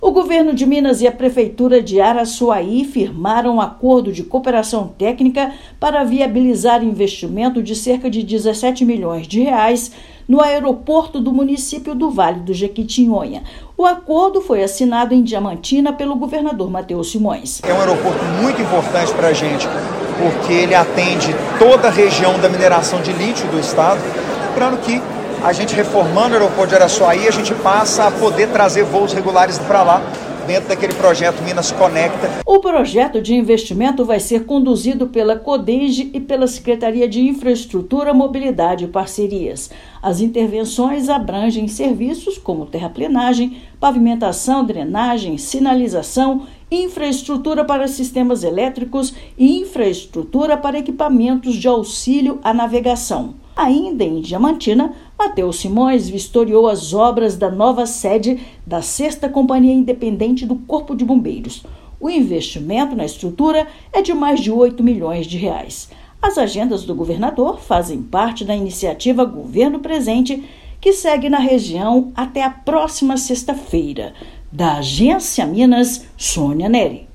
Impulsionada pelo desenvolvimento econômico a partir do projeto Vale do Lítio, região do Vale do Jequitinhonha receberá mais infraestrutura para ampliar logística, investimentos e turismo. Ouça matéria de rádio.